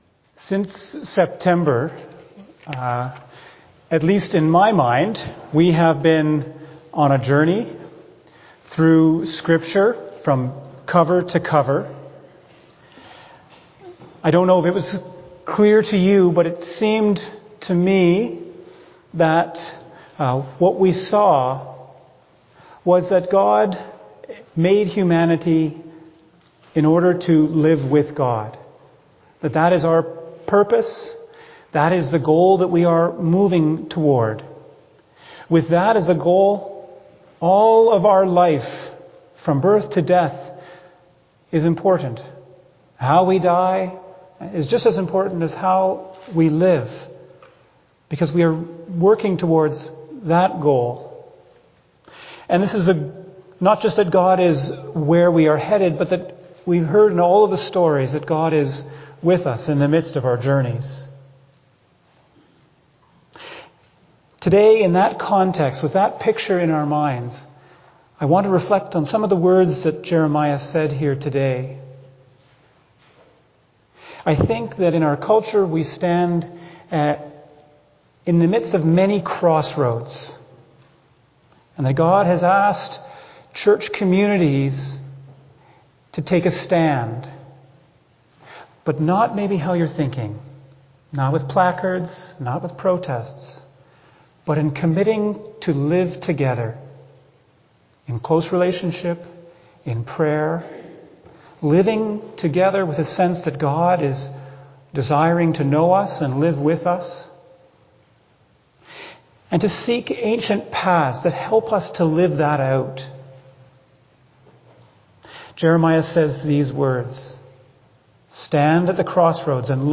2025 Sermon November 9 2025